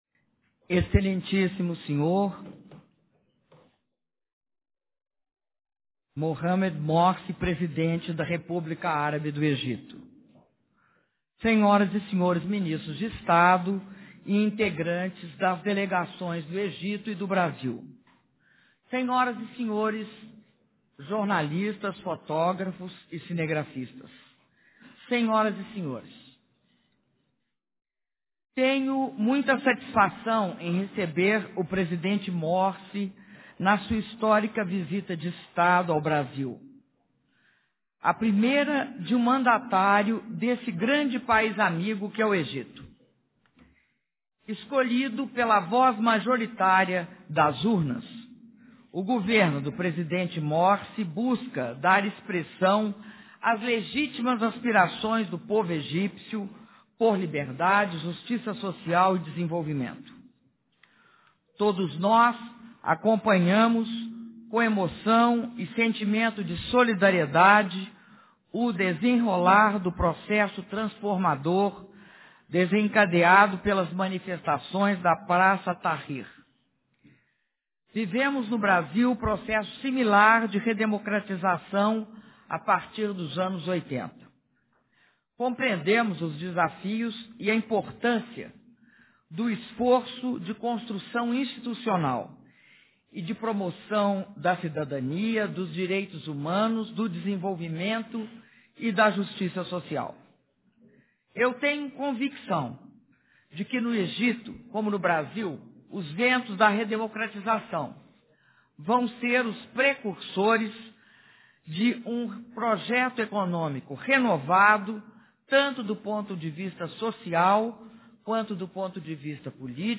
Declaração à imprensa da Presidenta da República, Dilma Rousseff, após cerimônia de assinatura de atos com o Presidente do Egito, Mohamed Morsi - Brasília/DF
Palácio do Planalto, 08 de maio de 2013